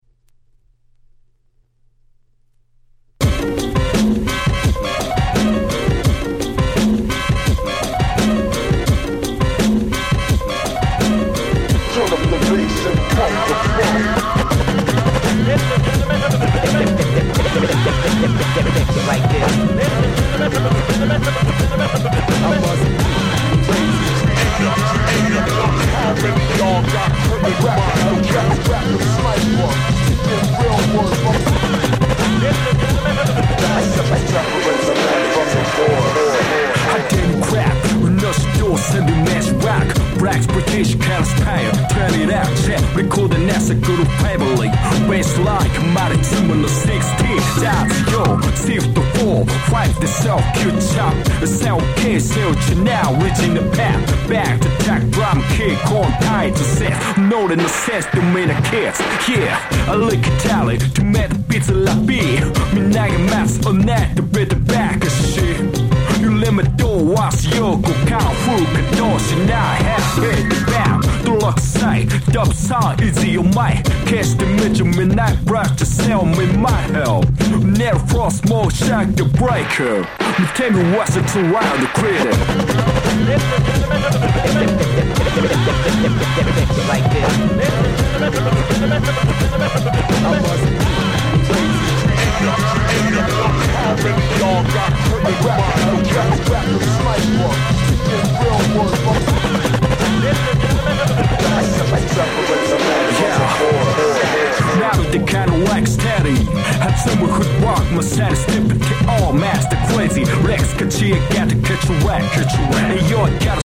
11' Dope Hip Hop !!!!!!!!!